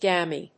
音節gam・ey 発音記号・読み方
/géɪmi(米国英語)/